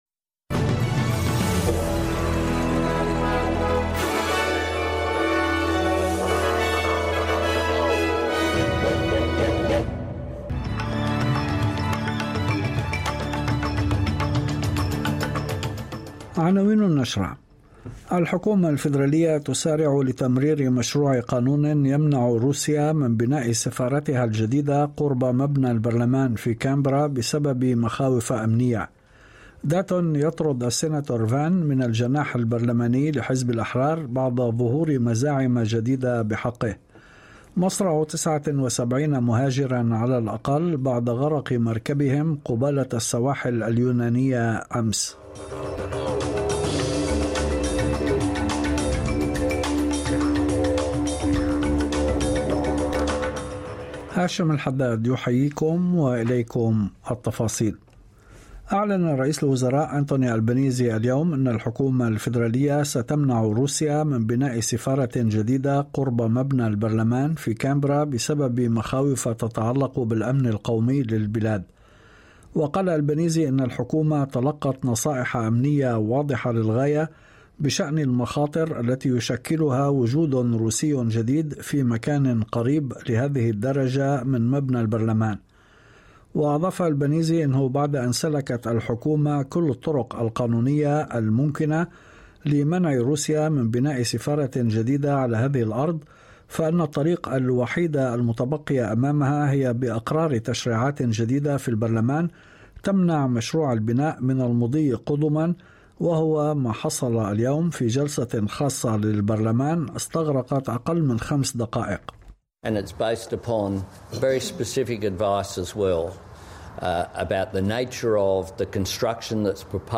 نشرة أخبار المساء 15/06/2023